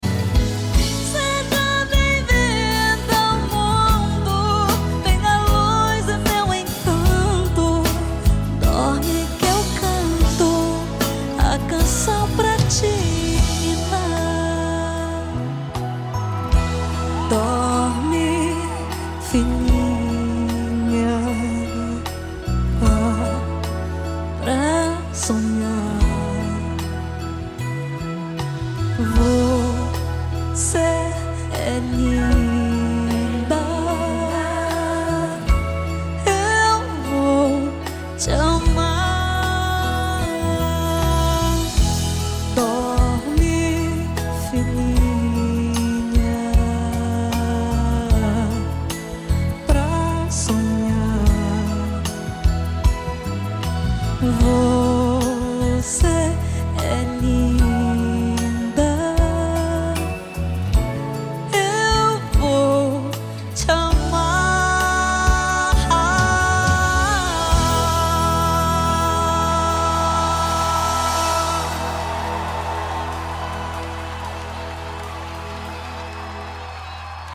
gravou um DVD em Portugal